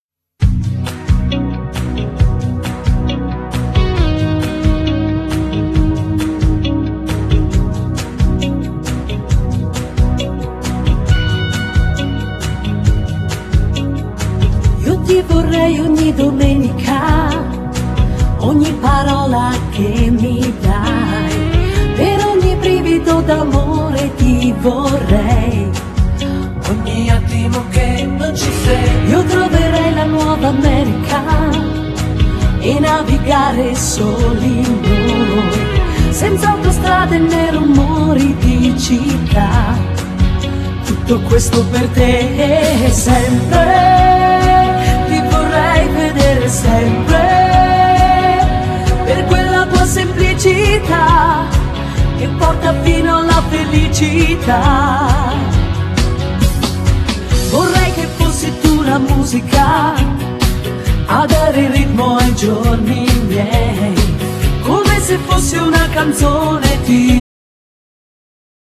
Genere : Folk